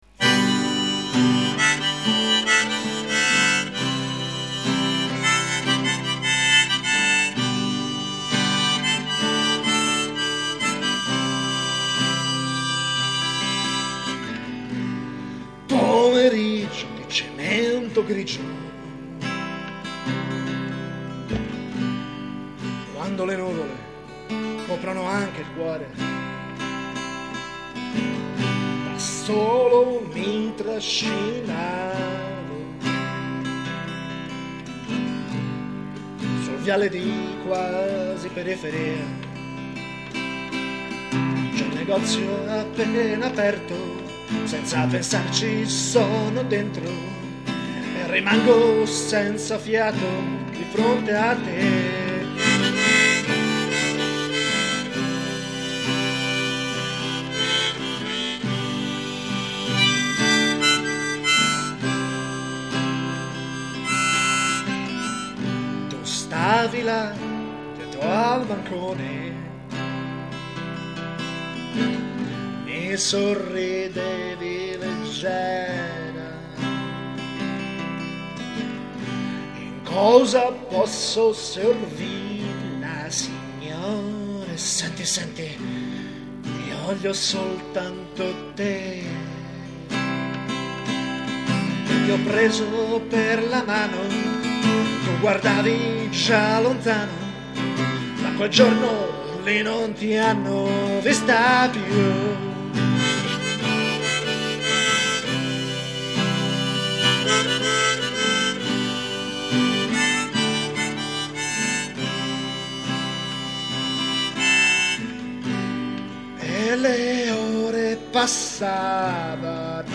Tra tutte ste bischerate, anch'io ho voluto cimentarmi nella composizione di una canzone d'amore, perche anche i vitelli hanno un cuore!